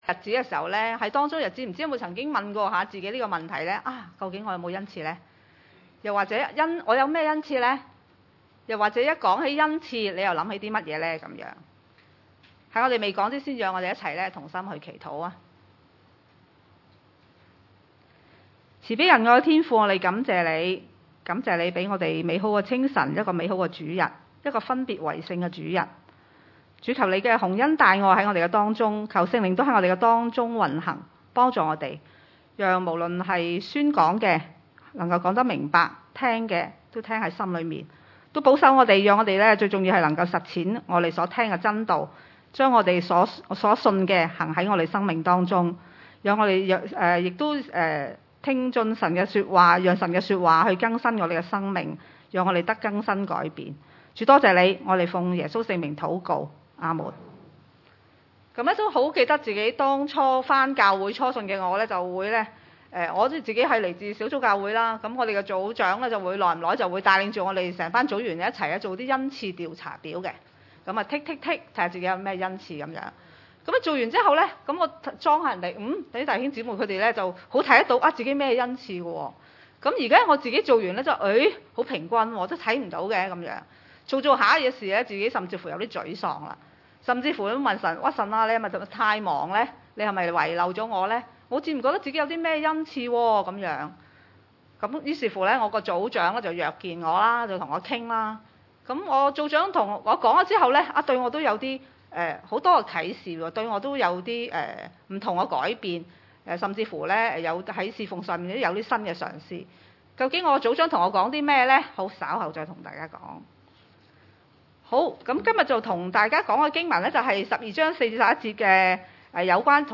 哥林多前書 12：4-11 崇拜類別: 主日午堂崇拜 4.